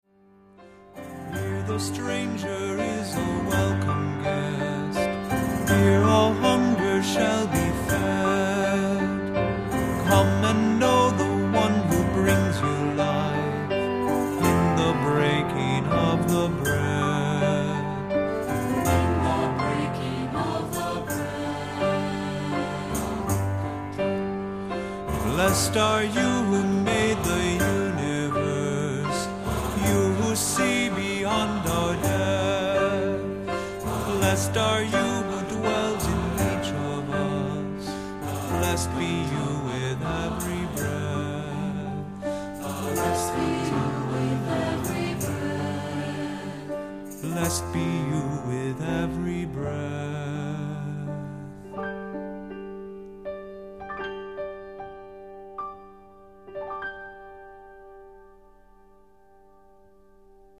Accompaniment:      Keyboard, Cello;Keyboard
Music Category:      Christian